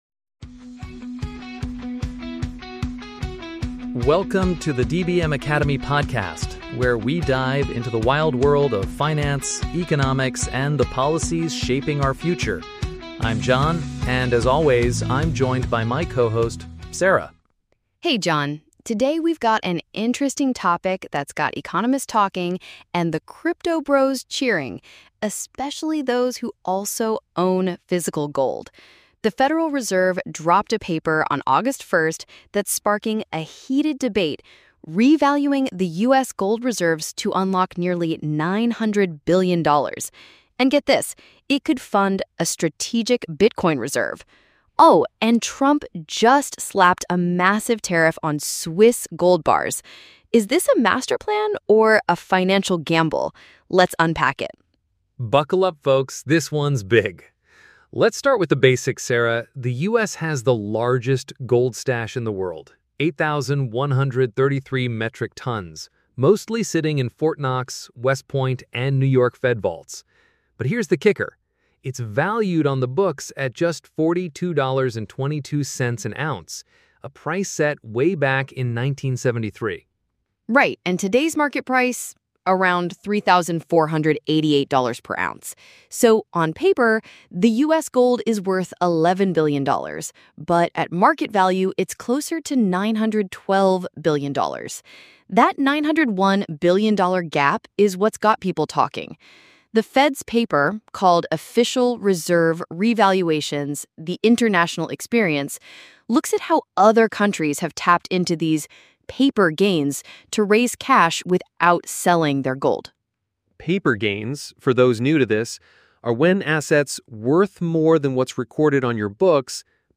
The hosts discuss the implications of this accounting move, including its potential to fund a Strategic Bitcoin Reserve (SBR), while weighing the risks of inflation and financial instability against its appeal as a temporary solution for national debt....